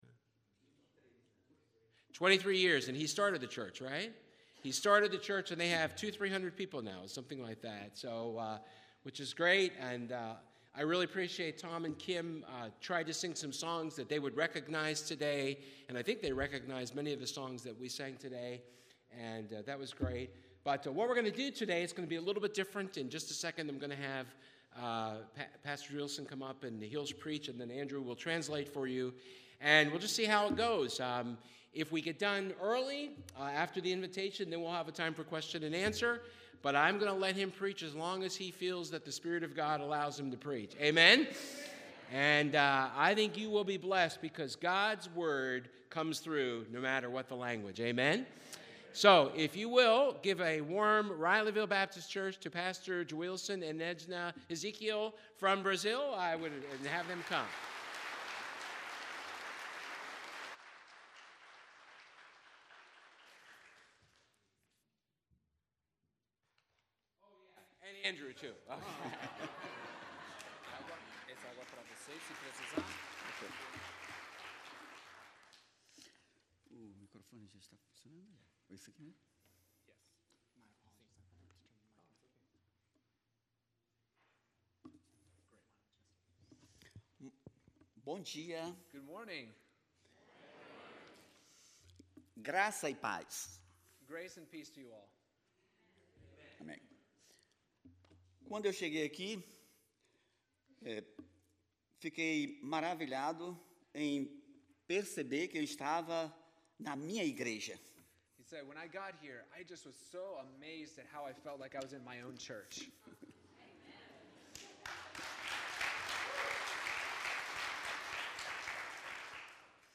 Passage: Mathew 16:13-19 Service Type: Sunday Service